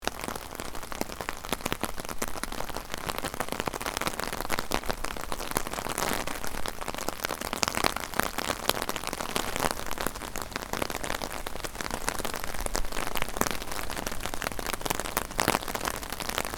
Download Free Umbrella Sound Effects | Gfx Sounds
Soft-rain-on-umbrella-rain-ambience-loop-2.mp3